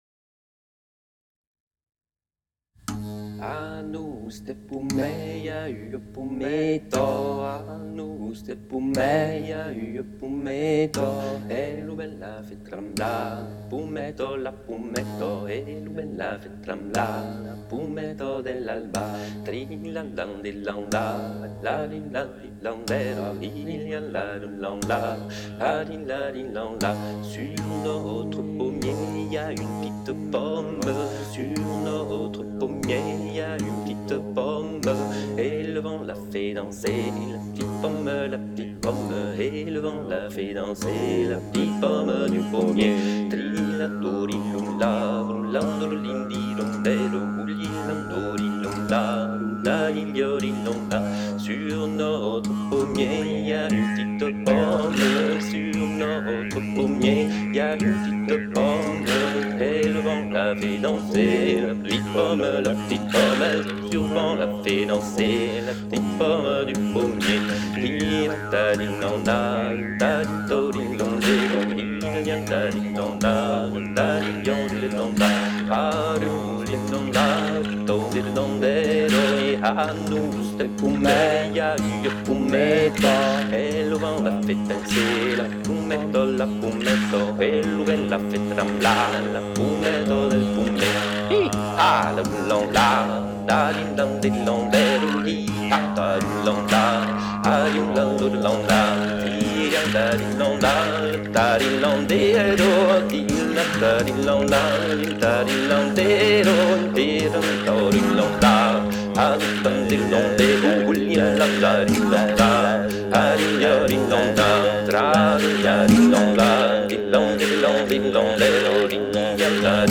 Genre : chanson-musique
Effectif : 1
Type de voix : voix d'homme
Production du son : chanté
Instrument de musique : tambourin à cordes